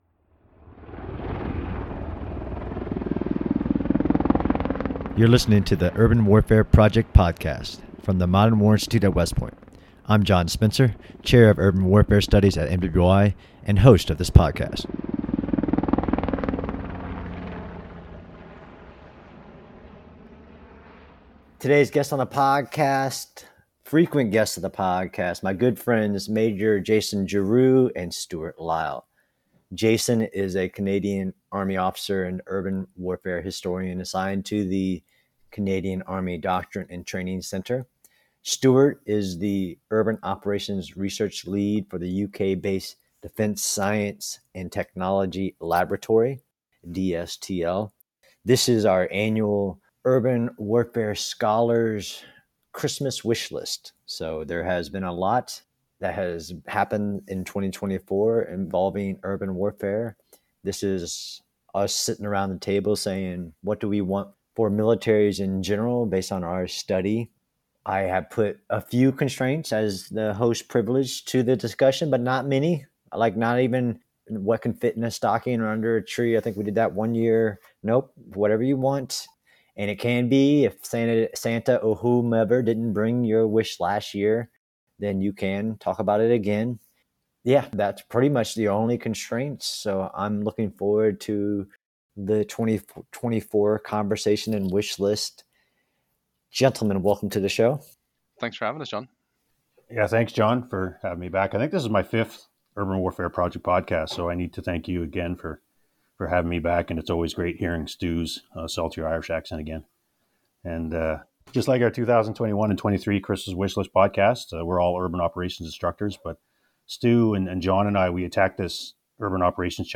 The Urban Warfare Project Podcast, from the Modern War Institute at West Point, features insightful discussions with scholars and practitioners as it sets out to explore the unique characteristics of urban warfare.